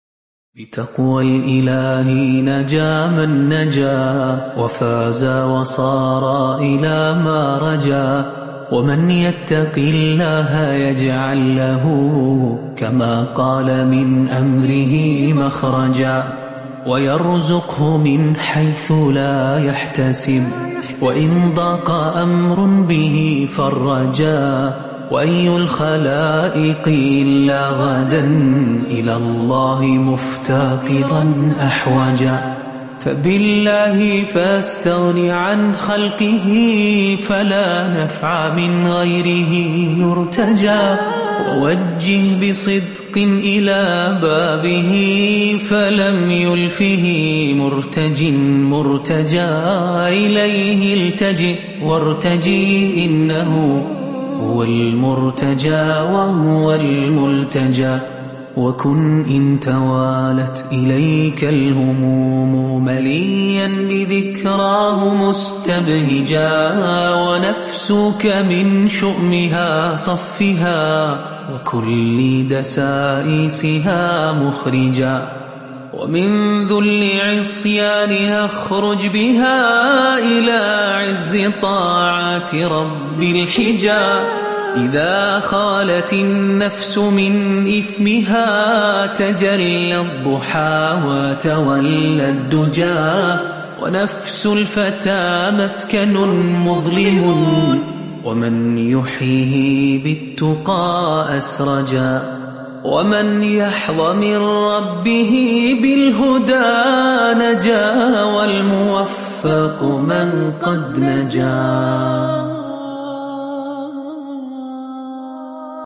شعر عربی
نشید